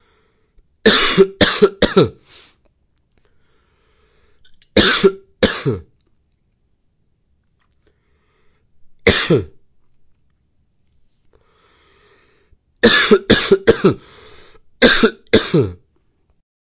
男咳嗽流感
描述：男咳嗽流感。 用Zoom H2录制。使用 Audacity 进行编辑。
标签： 生病 医生 健康 疾病 咳嗽 清晰 男性 鼻子 流感
声道立体声